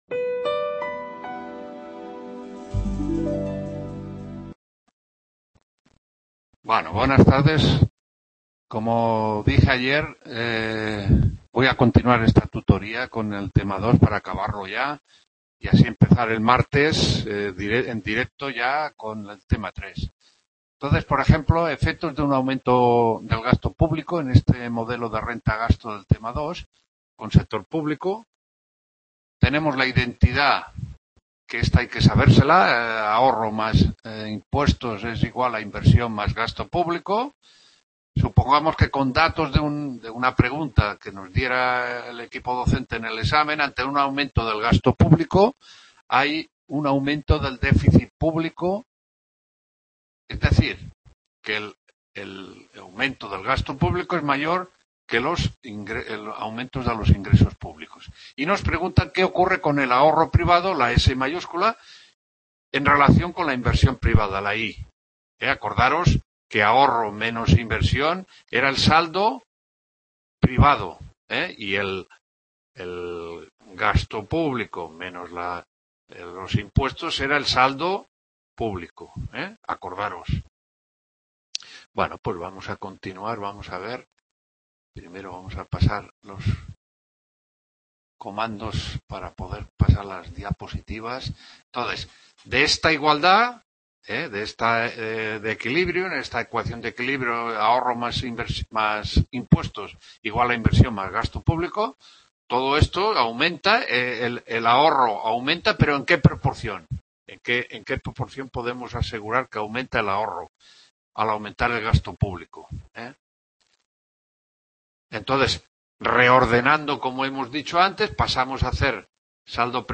6ª TUTORÍA (2ª PARTE) MODELO RENTA GASTO CON S.PÚBLICO… | Repositorio Digital